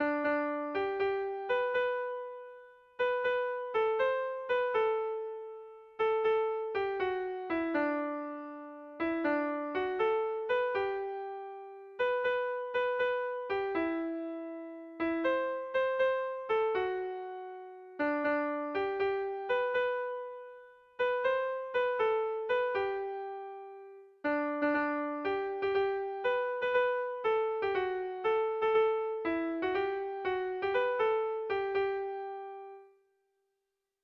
Bertso jarriak